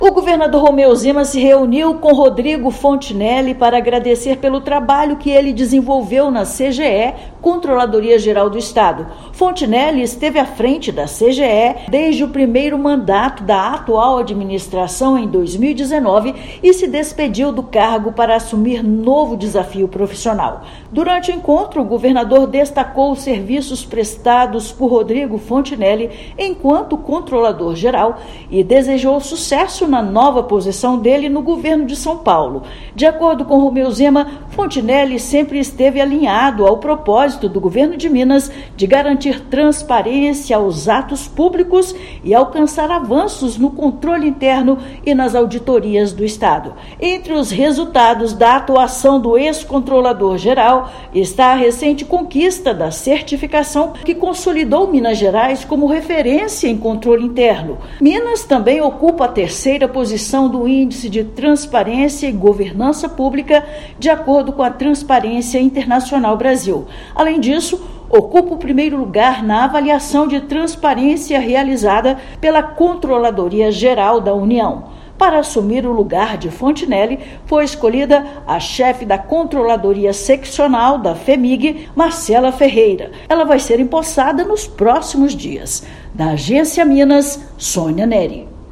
Durante a gestão do controlador-geral, Minas recebeu reconhecimento nacional e internacional em auditoria interna. Ouça matéria de rádio.